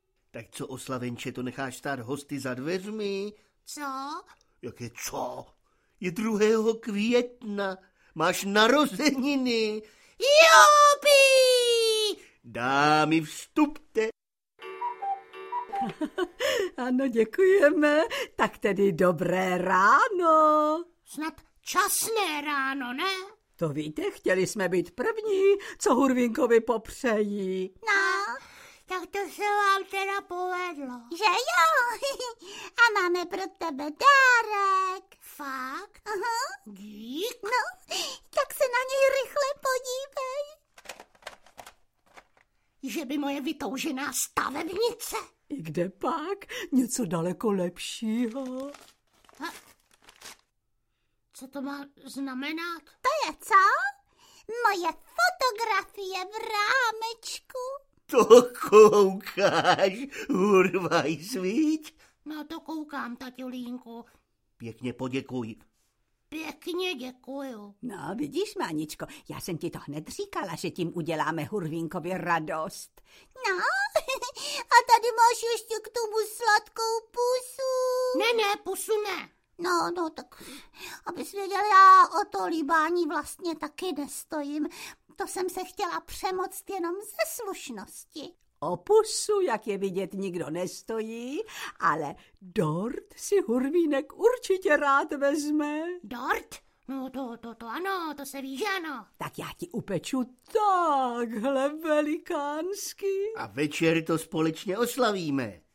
Audio kniha
Ukázka z knihy
Druhá část zvukového převedení nového loutkového seriálu Hurvínkův rok (podle stejnojmenné knížky) nabízí řadu humorných situací, sedm lehce zapamatovatelných písniček, k nímž hudbu složil a nahrál Emil Viklický i mistrovskou interpretaci Martina Kláska a Heleny Štáchové v jejich dvojrolích.